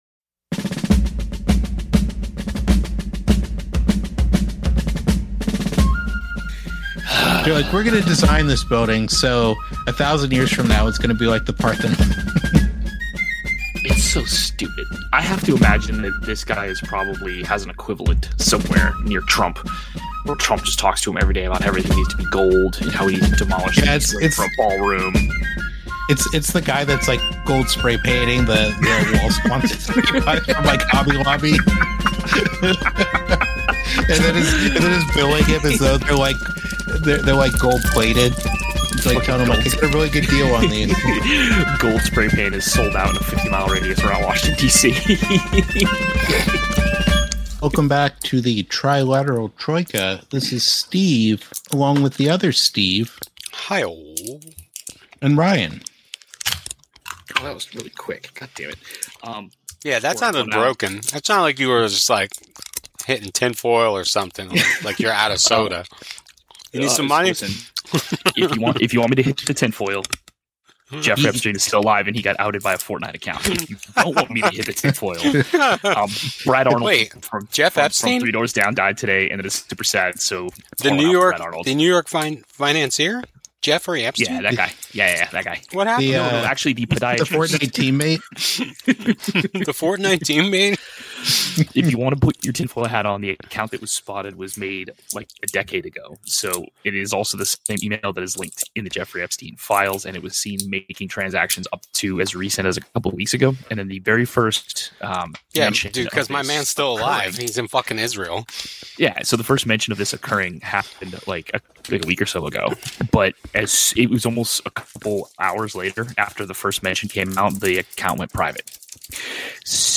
Disclaimer: We are three inebriated dudes(mostly) talking about history(mostly).